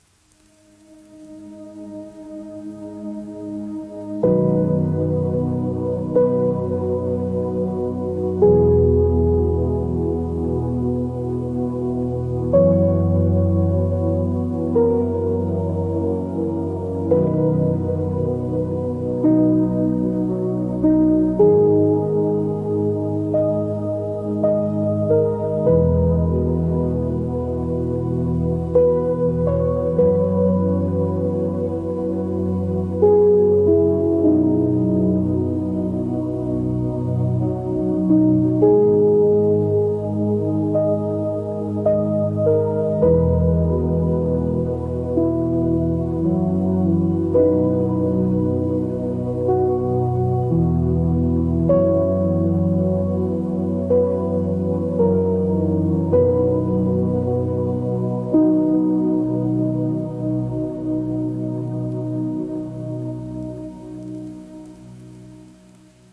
Healing Frequencies